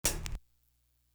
Boing Hat.wav